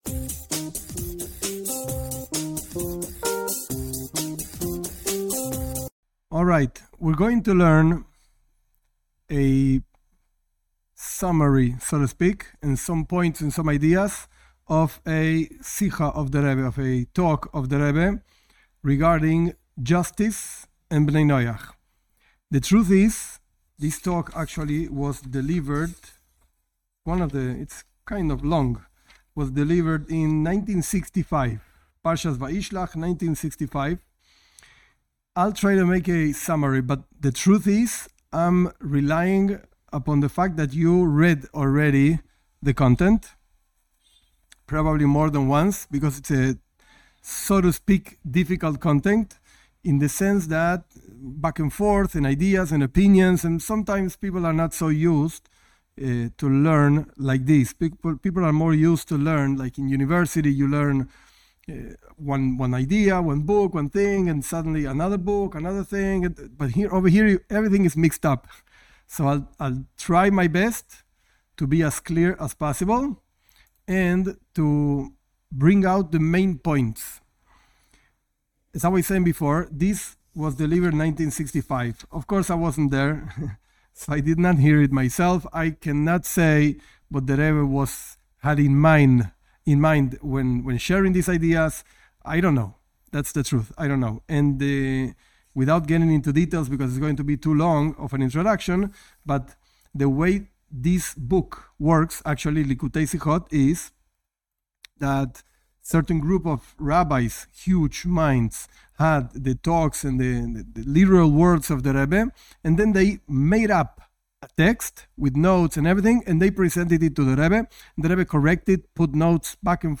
This class is an explanation of a talk by the Lubavitcher Rebbe on the subject.